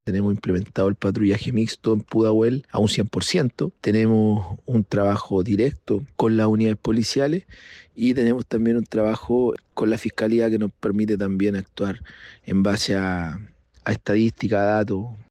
El alcalde de Pudahuel, Ítalo Bravo, explicó que desde el municipio han intensificado el trabajo conjunto con Carabineros, especialmente en fiscalizaciones para controlar el uso de armas. Además, señaló que han impulsado instancias de mediación comunitaria para abordar conflictos entre vecinos y evitar que escalen hacia hechos de violencia.